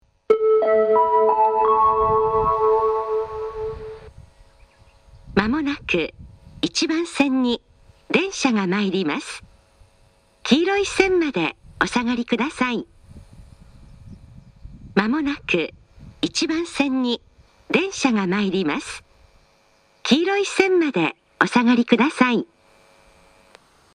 スピーカーが上下兼用なので、交換のある列車の場合、放送が被りやすいです。
自動放送
仙石型（女性）
接近放送